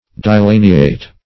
Search Result for " dilaniate" : The Collaborative International Dictionary of English v.0.48: Dilaniate \Di*la"ni*ate\, v. t. [L. dilaniatus, p. p. of dilaniare to dilacerate; di- = dis- + laniare to tear to pieces.] To rend in pieces; to tear.
dilaniate.mp3